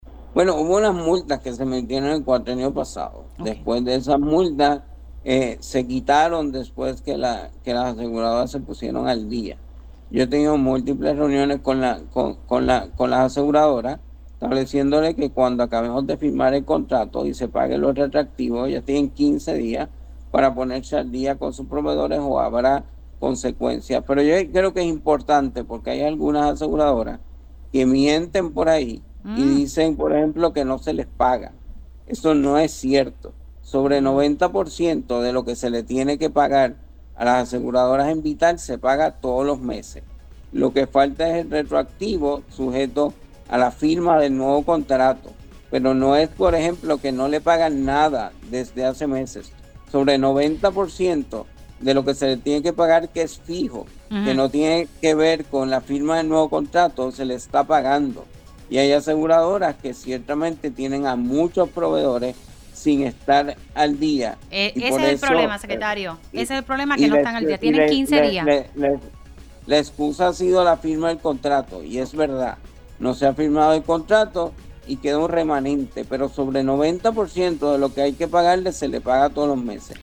309-VICTOR-RAMOS-SEC-SALUD-ADVIERTE-HABRA-MULTAS-ASESGURADORAS-QUE-NO-PAGUEN-A-PROVEEDORES-SEGUN-LA-LEY.mp3